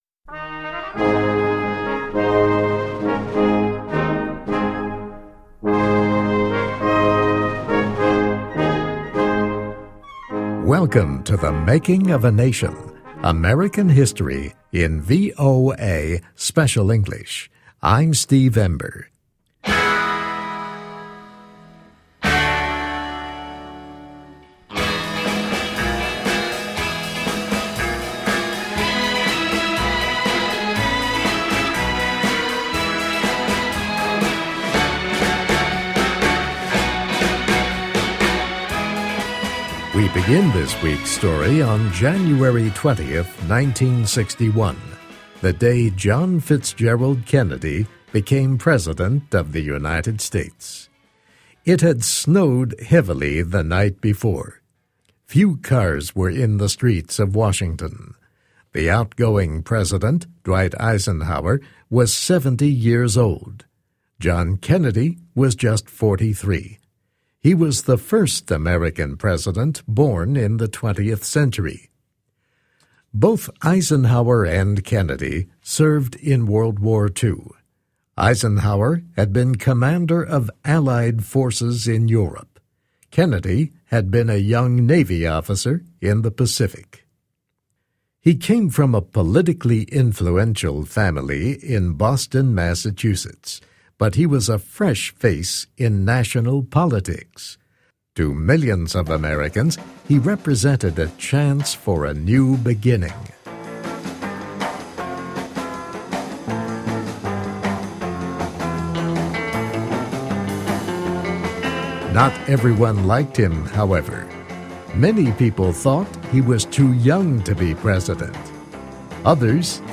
Welcome to THE MAKING OF A NATION �C American history in VOA Special English.